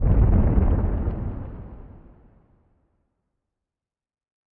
Minecraft Version Minecraft Version latest Latest Release | Latest Snapshot latest / assets / minecraft / sounds / ambient / nether / nether_wastes / mood4.ogg Compare With Compare With Latest Release | Latest Snapshot